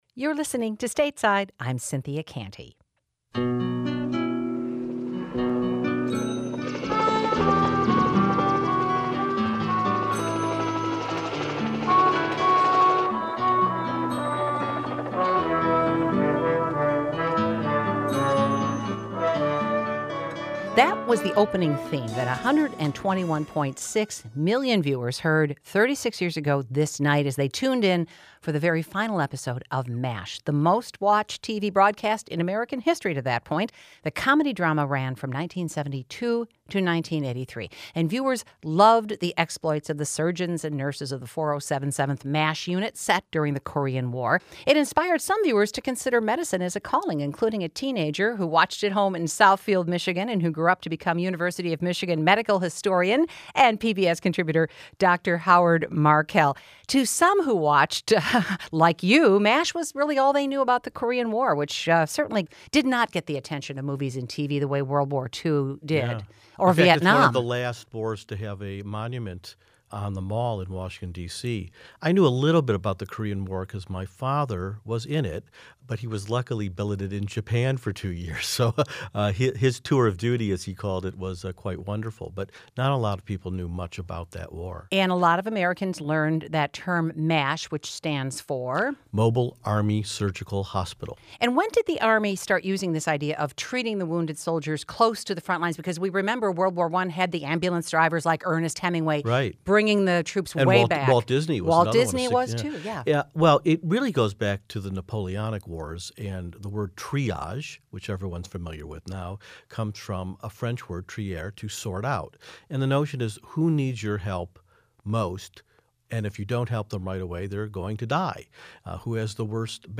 Stateside’s conversation